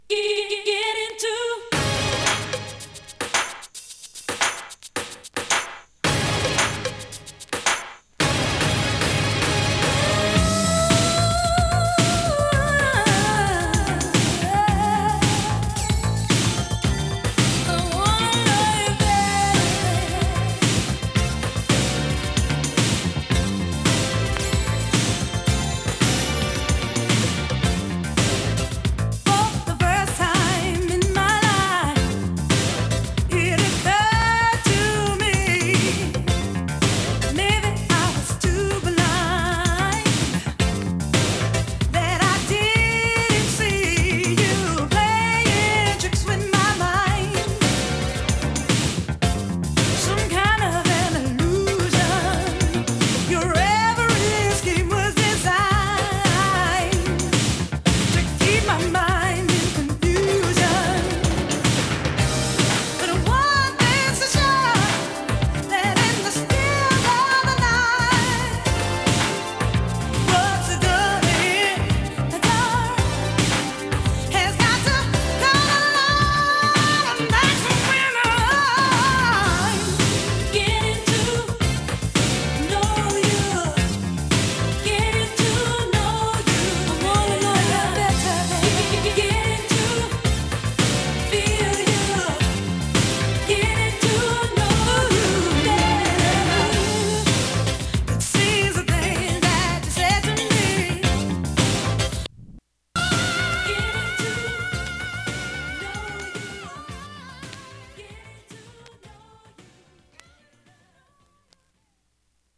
ゴージャスでファンク度の高い